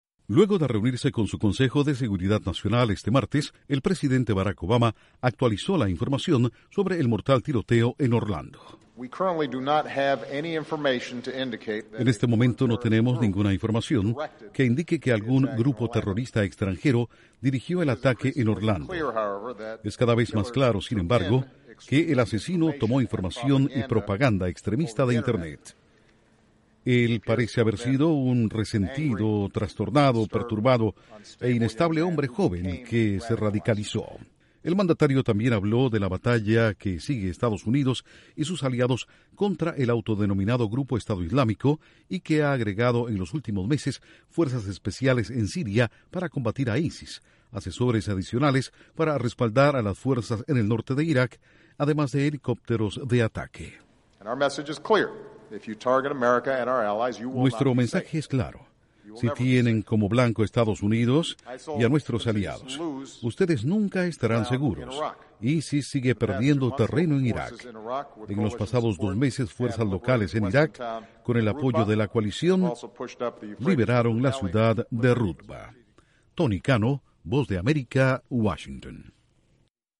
Inteligencia de Estados Unidos dice que el asesino de Orlando se radicalizó por medio de Internet. Informa desde la Voz de América en Washington